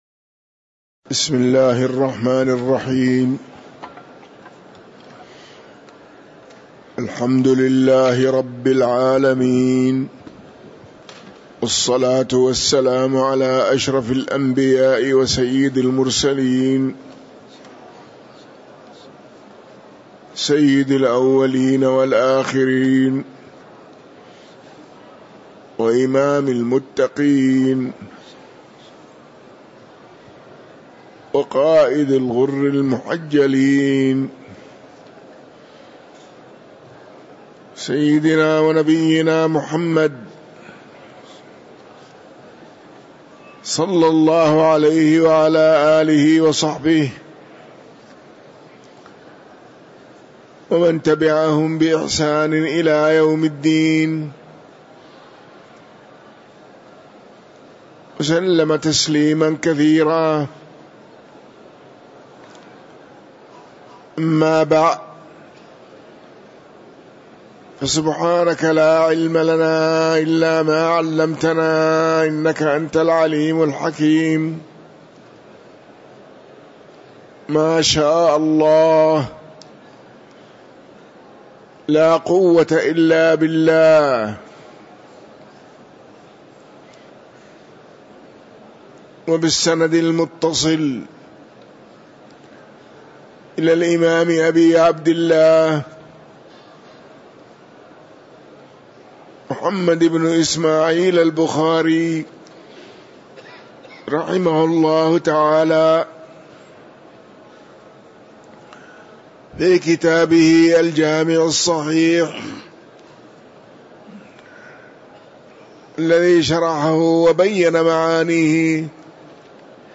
تاريخ النشر ٢٢ جمادى الآخرة ١٤٤٤ هـ المكان: المسجد النبوي الشيخ